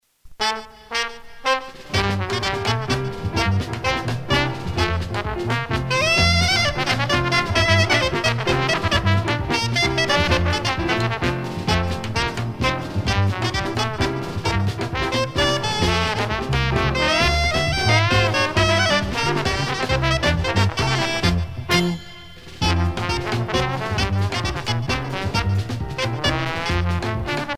danse : biguine
Pièce musicale éditée